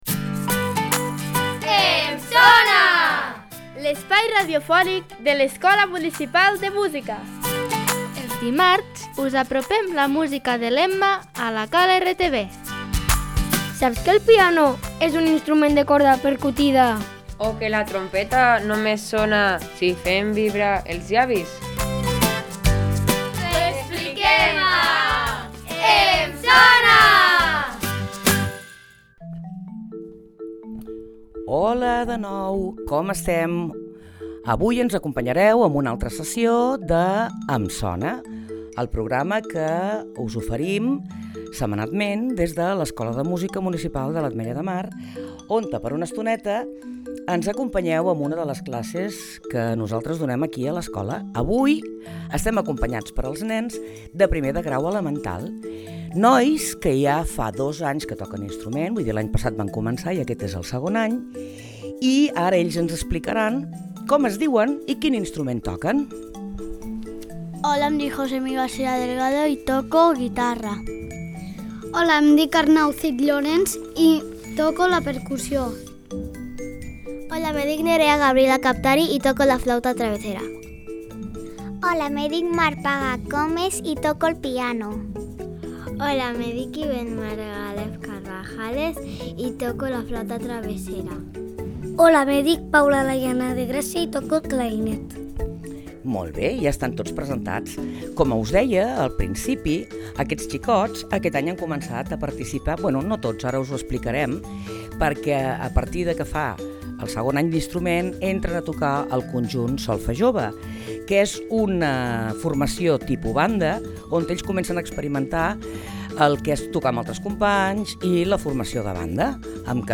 Gènere: Kids, Comedy, Soundtrack.